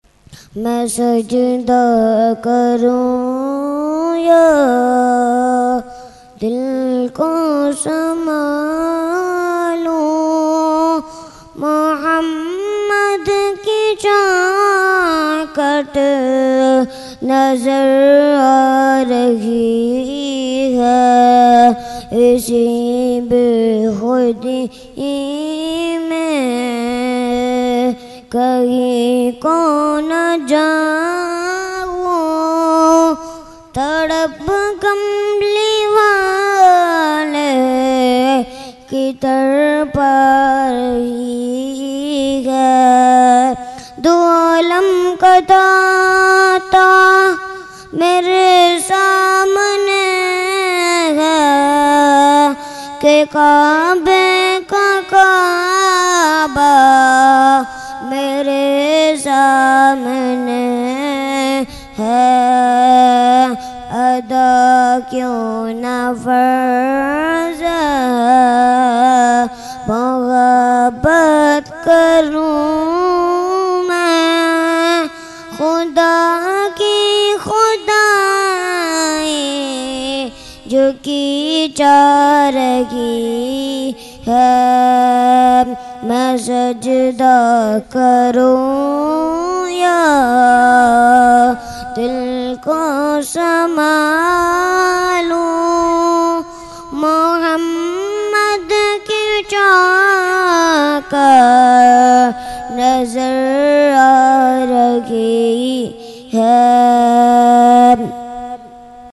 Mehfil e Jashne Subhe Baharan held on 16 September 2024 at Dargah Alia Ashrafia Ashrafabad Firdous Colony Gulbahar Karachi.
Category : Naat | Language : UrduEvent : Jashne Subah Baharan 2024